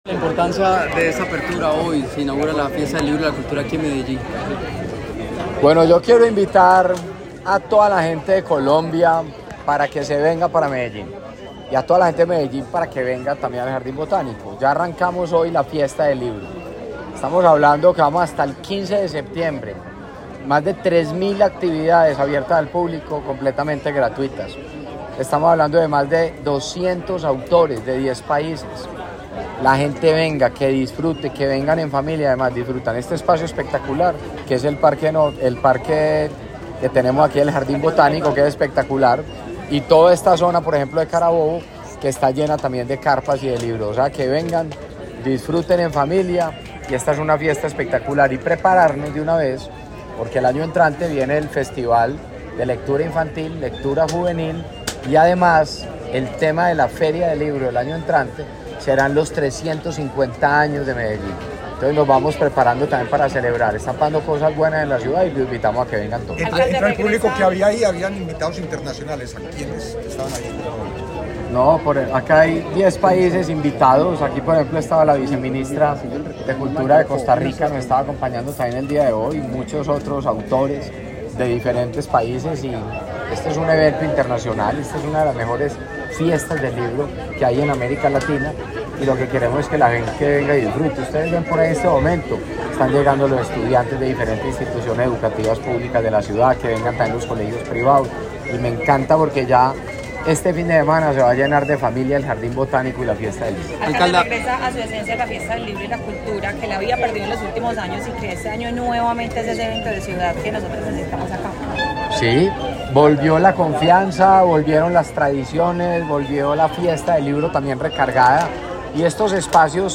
Palabras de Federico Gutiérrez Zuluaga, alcalde de Medellín La 18.ª Fiesta del Libro y la Cultura de Medellín abre sus puertas con el anuncio de que en 2025 la ciudad tendrá su primera Feria Internacional del Libro Infantil y Juvenil.